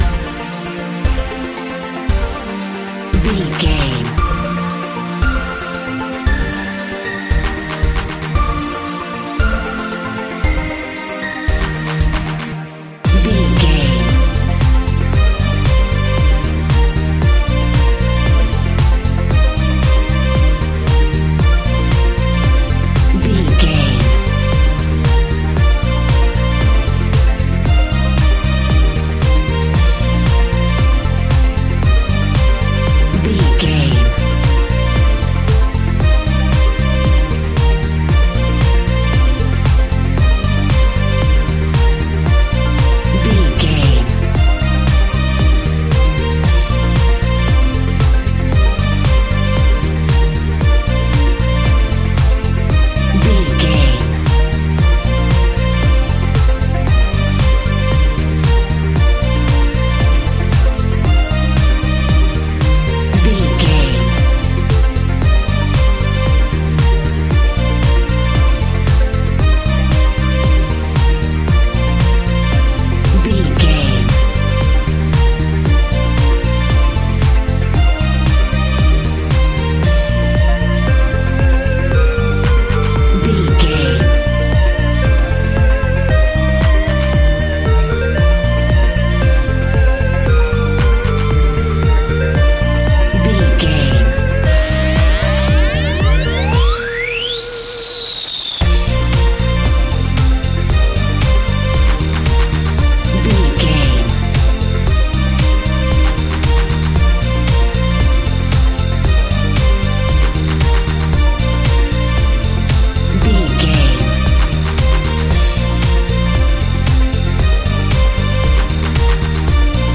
Ionian/Major
B♭
driving
energetic
uplifting
hypnotic
funky
groovy
drum machine
synthesiser
electronic music
techno music
synth bass
synth pad
robotic